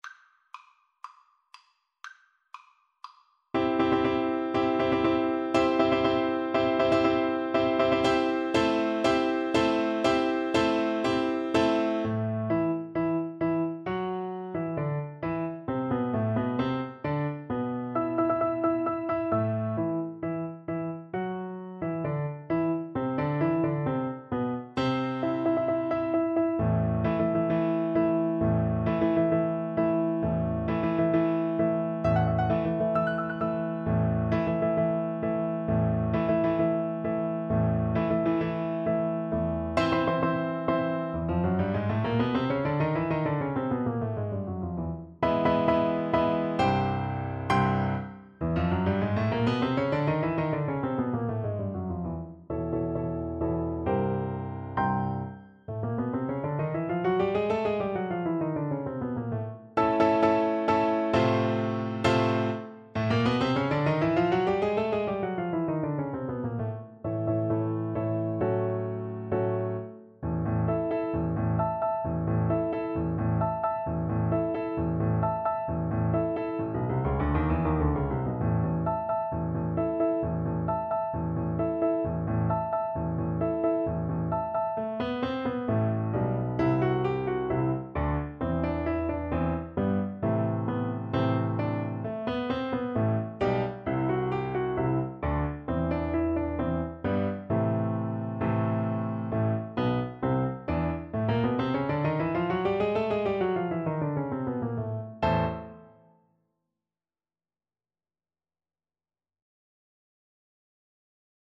4/4 (View more 4/4 Music)
Allegro non troppo (View more music marked Allegro)
C major (Sounding Pitch) (View more C major Music for Oboe )
Classical (View more Classical Oboe Music)